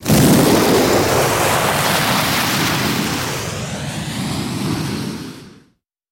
На этой странице собраны разнообразные звуки Змея Горыныча: от грозного рыка до зловещего шипения.
Звук: Змей Горыныч извергает пламя из пасти